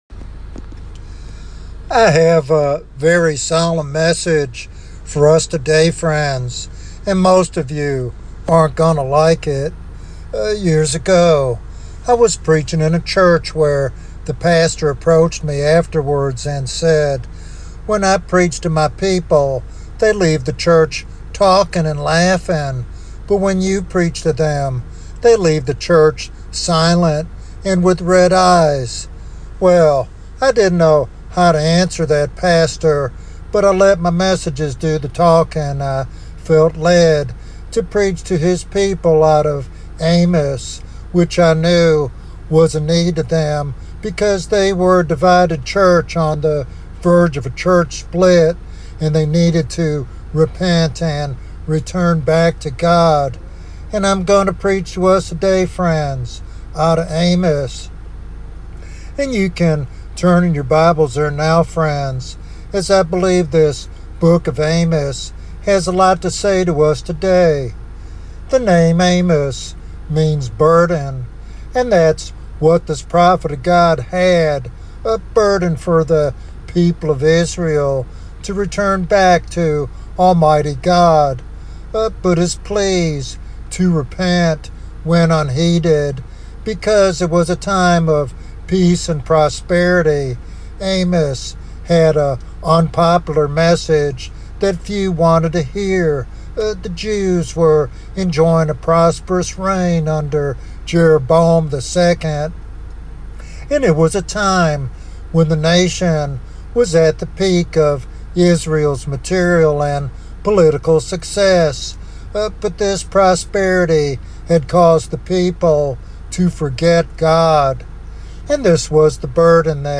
This topical sermon challenges listeners to recognize the seriousness of divine judgment and the necessity of repentance.